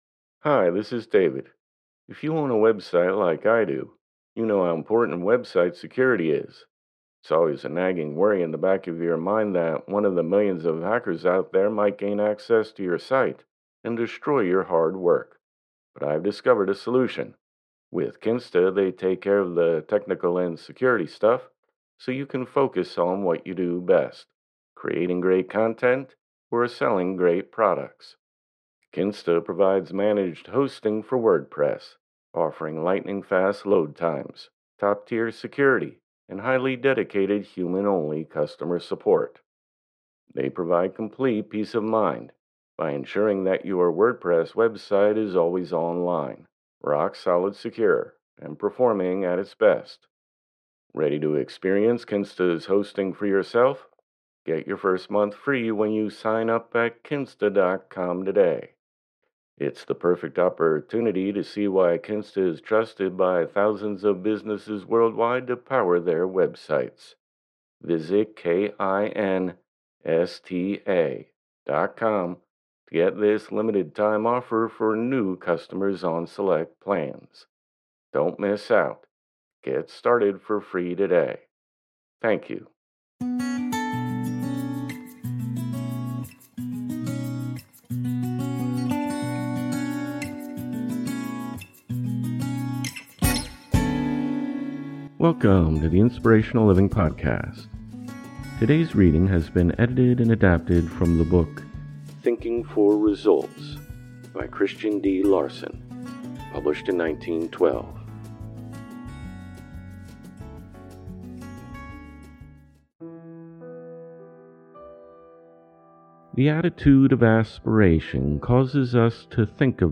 Look no further than the most motivational self-help authors of the past. Inspiring readings from James Allen, Napoleon Hill, Hellen Keller, Booker T. Washington, Khalil Gibran, Marcus Aurelius, and more.